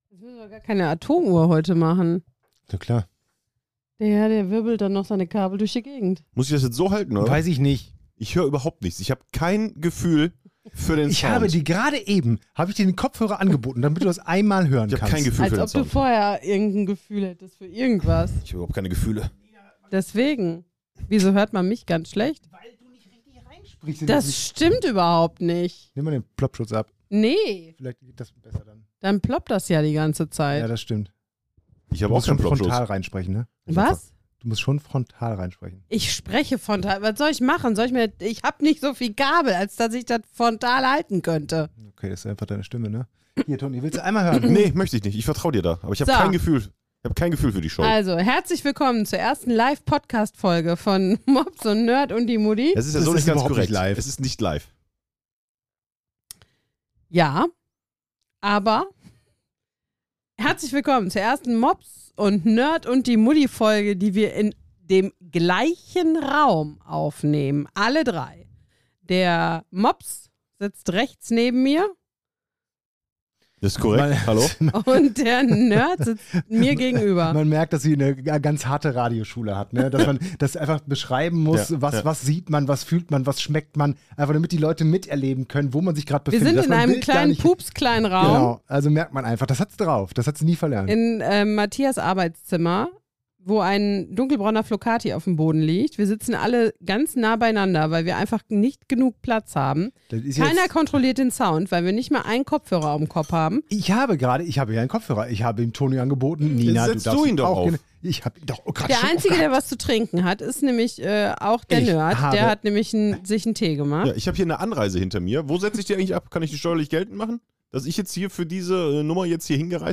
Zum ersten Mal zu dritt im selben Raum – was kann da schon schiefgehen?
Am Ende gibt’s sogar ein selbstgebautes KI-Intro. Qualität? Joa.